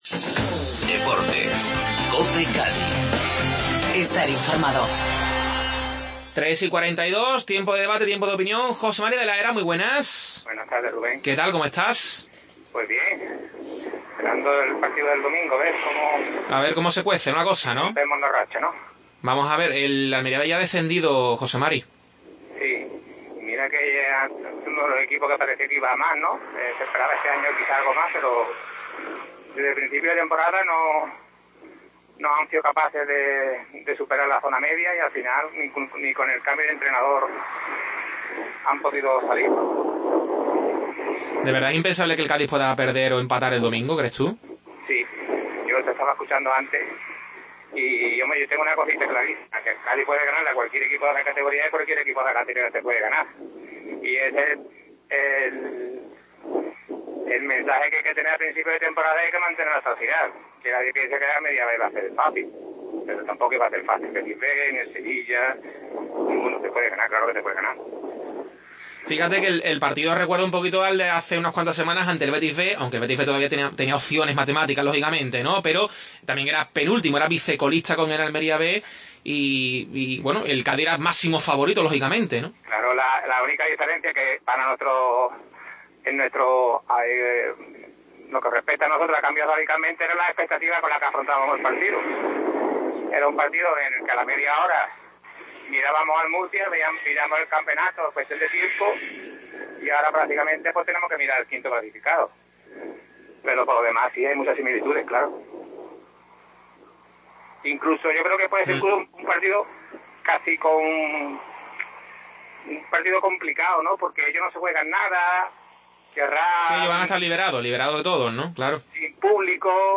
Tiempo de debate